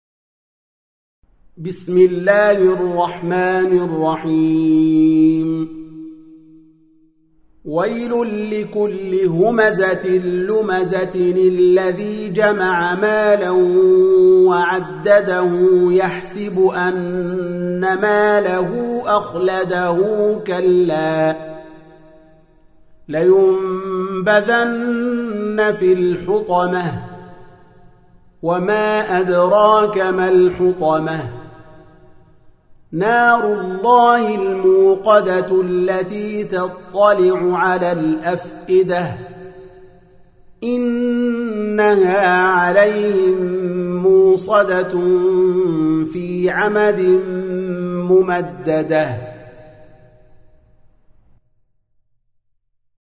104. Surah Al-Humazah سورة الهمزة Audio Quran Tarteel Recitation
Surah Repeating تكرار السورة Download Surah حمّل السورة Reciting Murattalah Audio for 104. Surah Al-Humazah سورة الهمزة N.B *Surah Includes Al-Basmalah Reciters Sequents تتابع التلاوات Reciters Repeats تكرار التلاوات